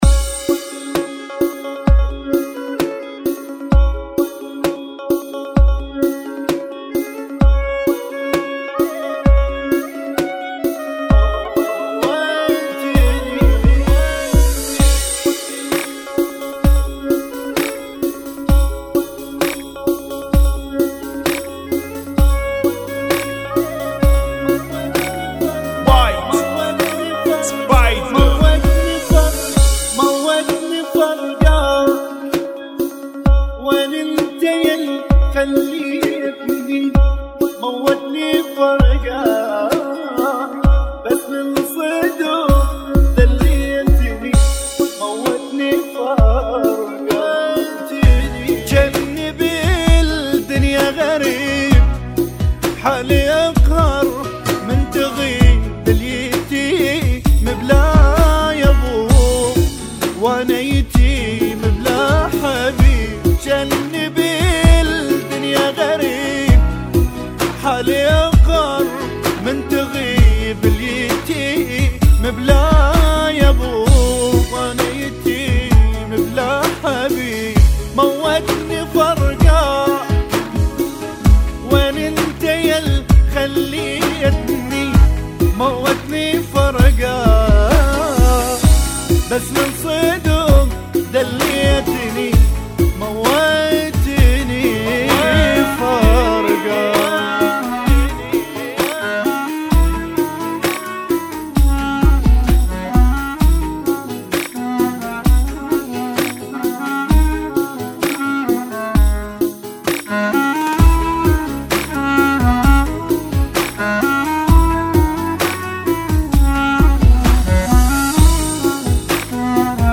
Funky [ 65 Bpm ]